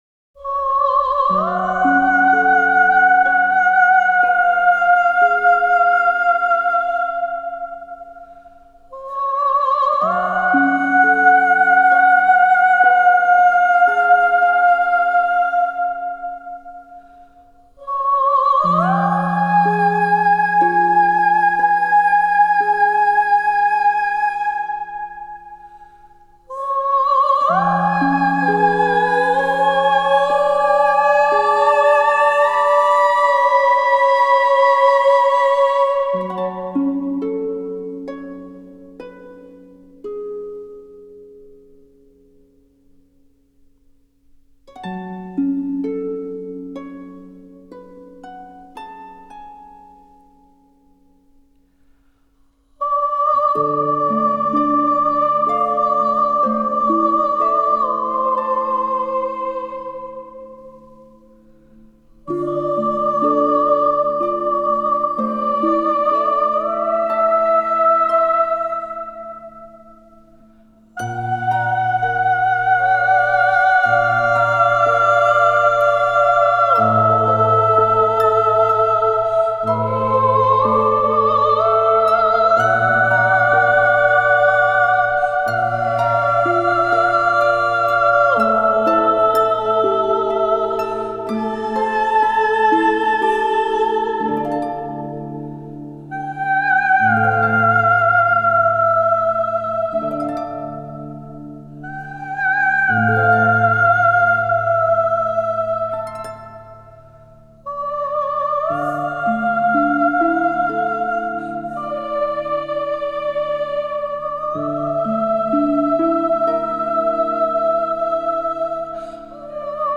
موسیقی بی‌کلام